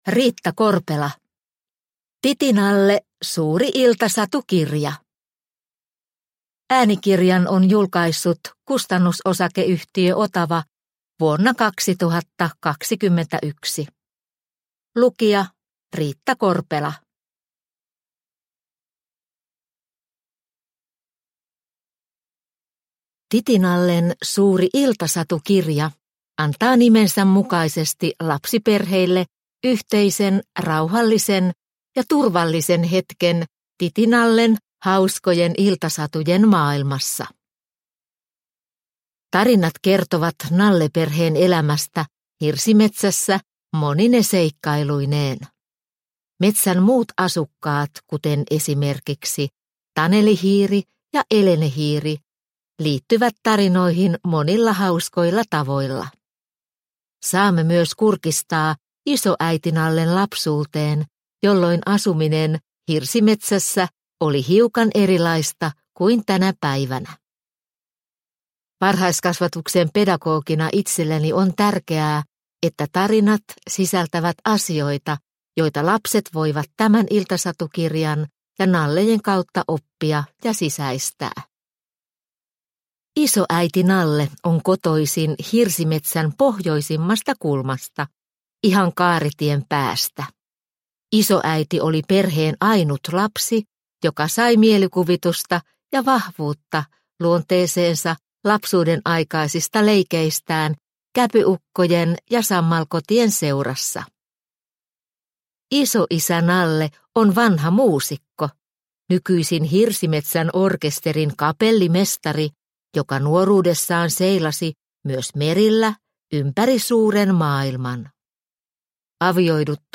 TI-TI Nalle Suuri iltasatukirja – Ljudbok